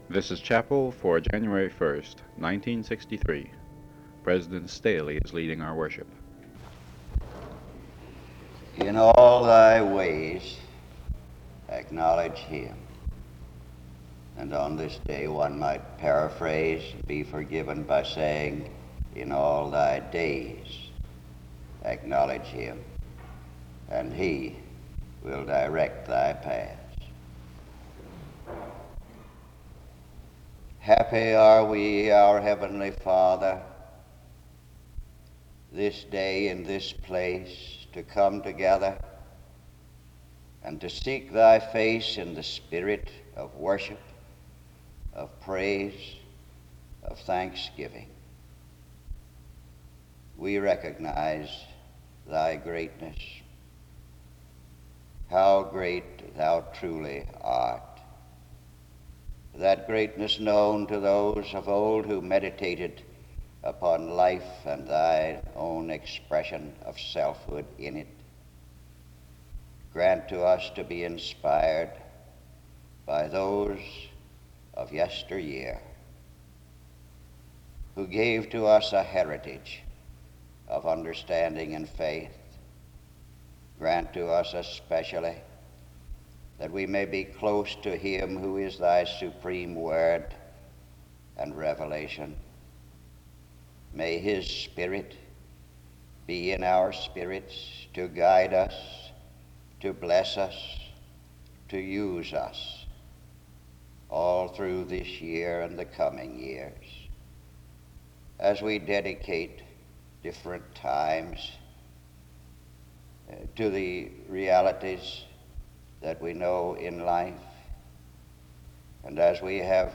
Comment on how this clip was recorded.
[This is only a partial message.]